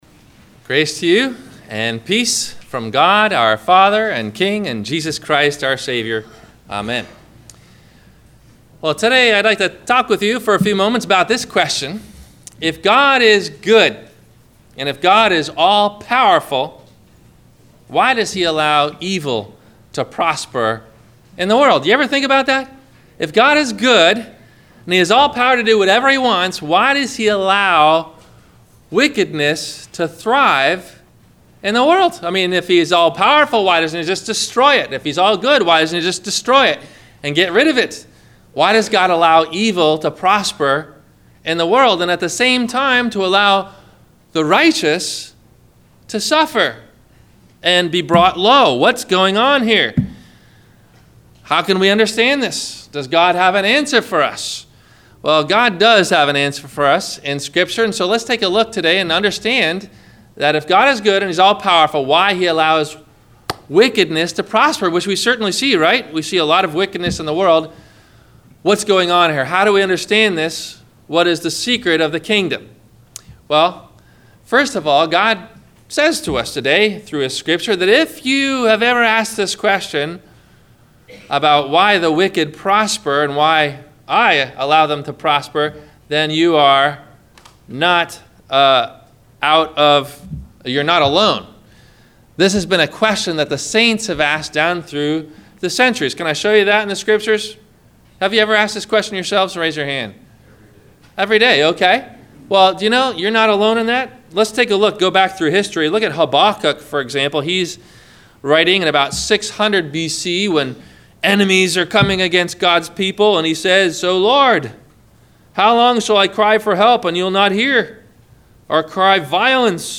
Why Does God Allow Evil To Prosper? - Sermon - May 21 2017 - Christ Lutheran Cape Canaveral